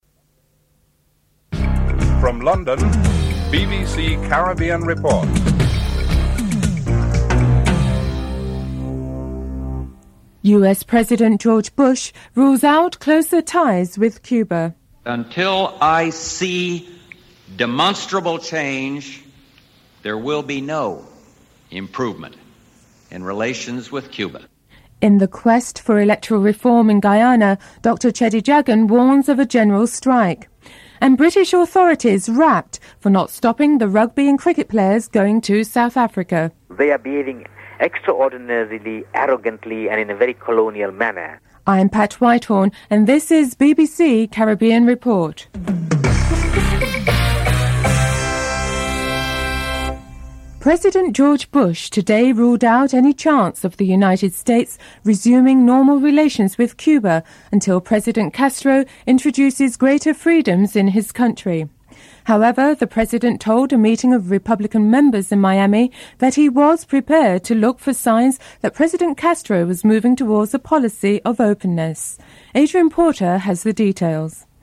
1. Headlines (00:46-01:29)